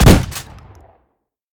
pump-shot-2.ogg